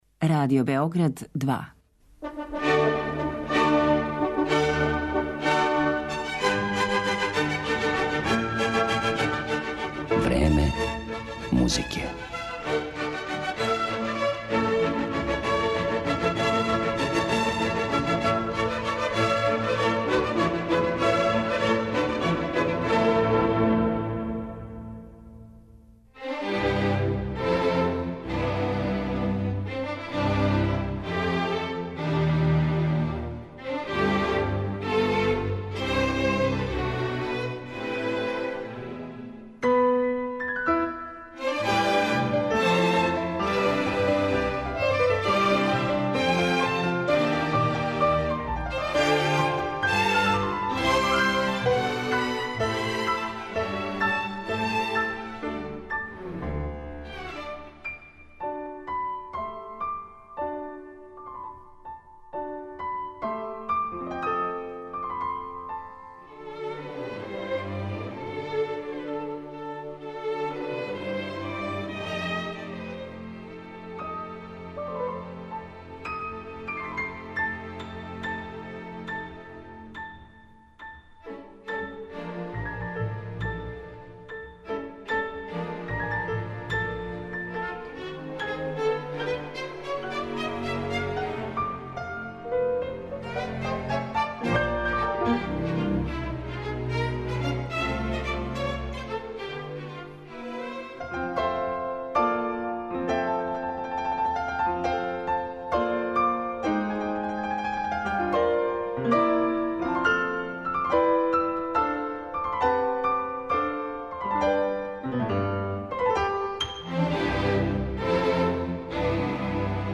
може да се доживи као мост између неокласицизма, постимпресионизма и модерних тенденција 30-их година прошлог века
пијанисте